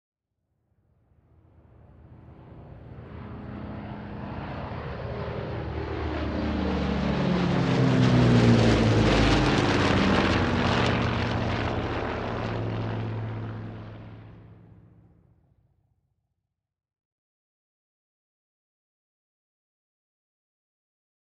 Airplane Dakota overhead propeller